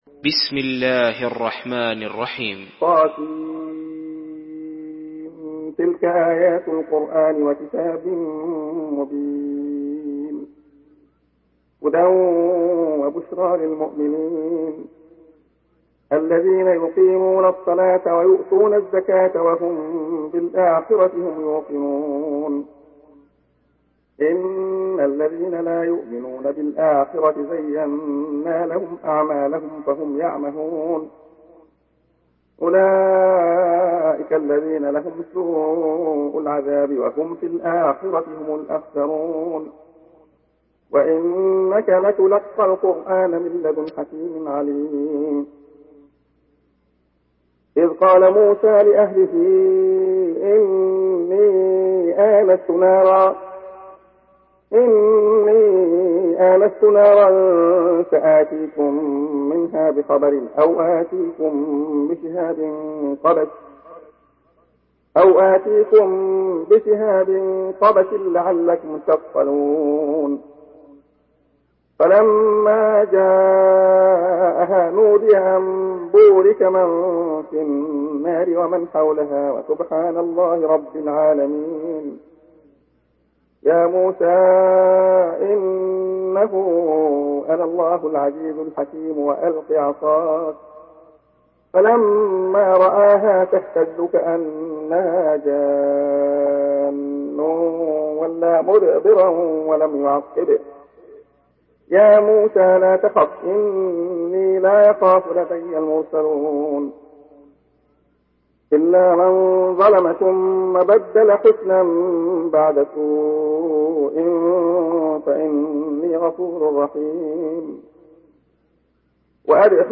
Surah An-Naml MP3 by Abdullah Khayyat in Hafs An Asim narration.
Murattal